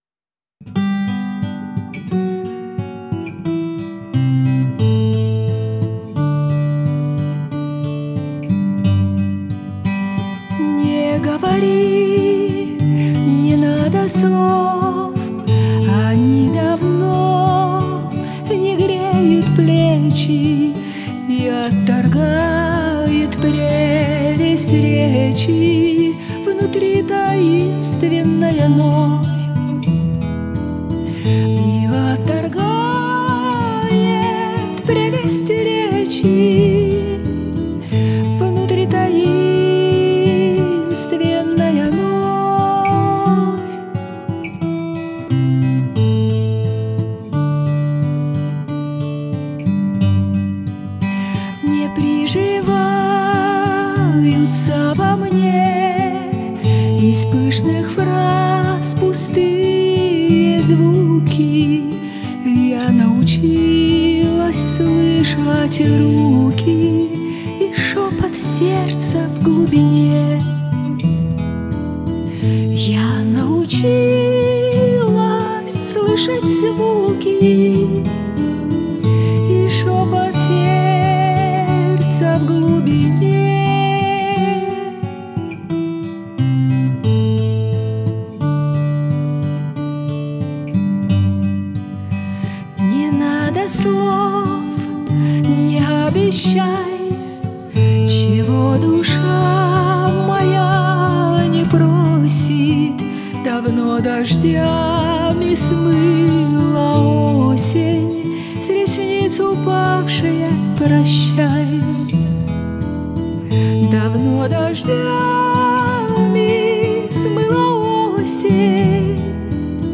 (классическая и эстрадная), использованные